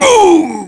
Ricardo-Vox_Damage_kr_02.wav